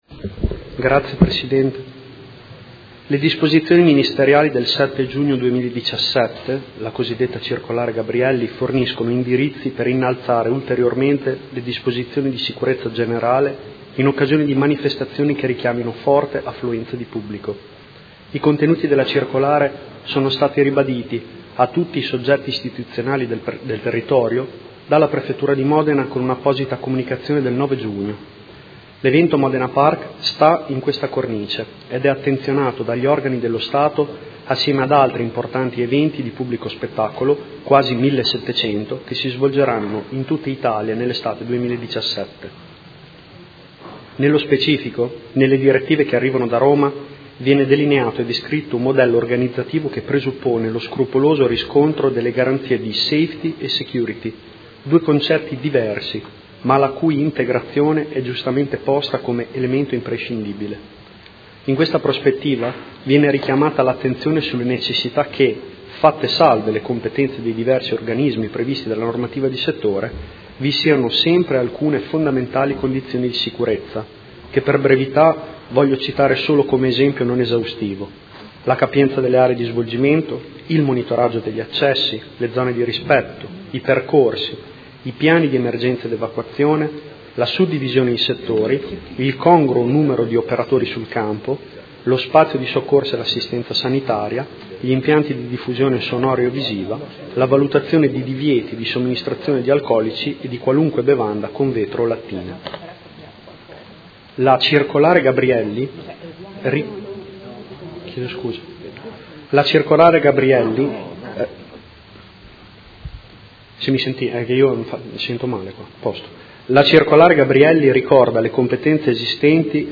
Seduta del 15/06/2017. Risponde a interrogazione della Consigliera Santoro (Idea-PL) avente per oggetto: Concerto di Vasco Rossi: come verrà garantita la sicurezza?